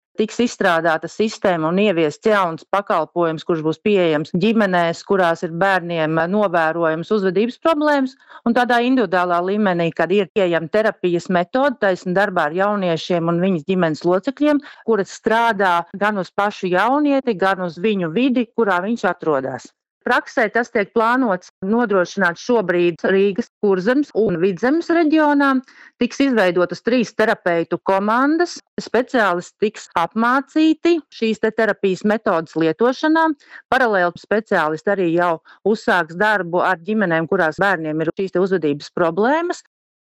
Saruna